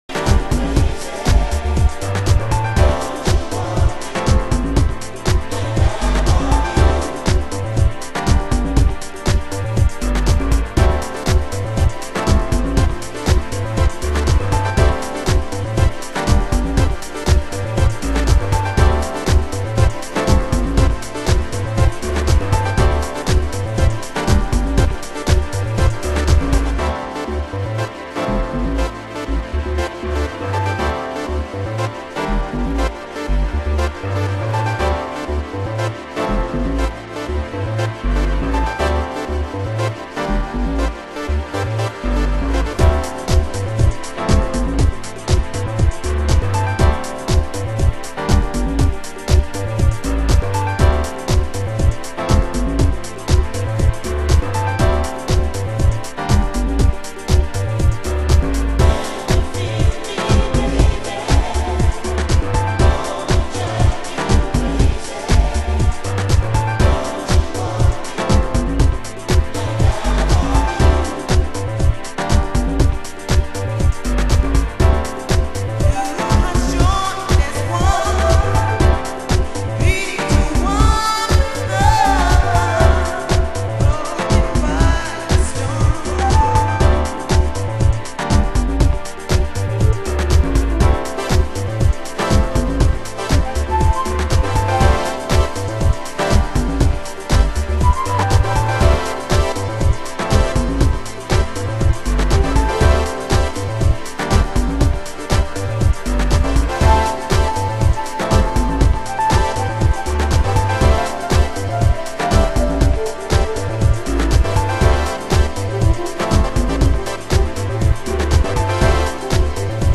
HOUSE MUSIC
中盤　　　盤質：少しチリパチノイズ